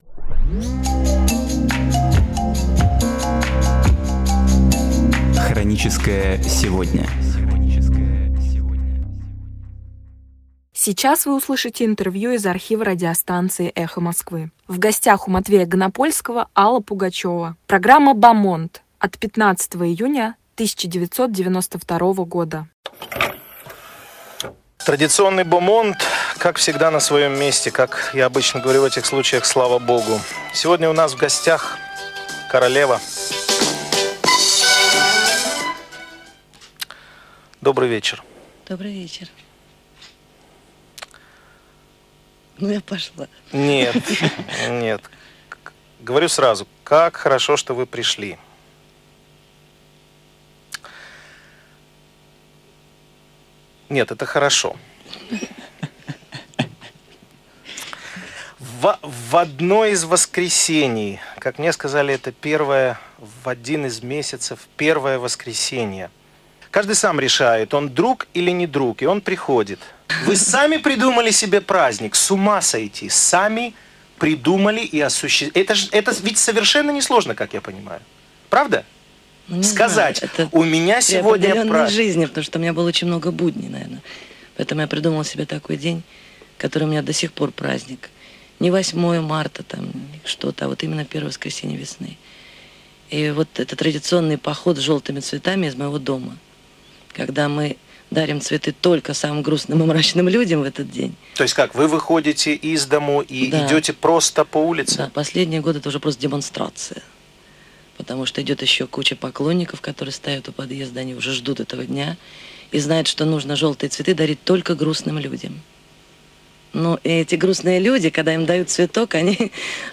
«Хроническое сегодня»: Архивное интервью Аллы Пугачевой
Программы из архива «Эха Москвы»